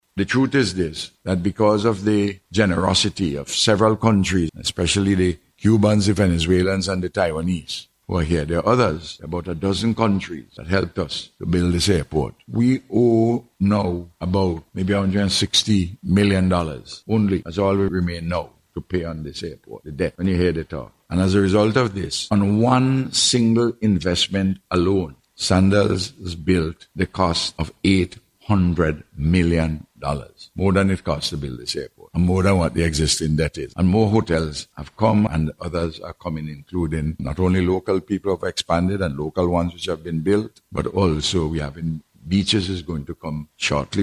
He made this statement during a recent ceremony which was held, for the Grand Opening of the Garifuna C.I.P Lounge at the Argyle International Airport.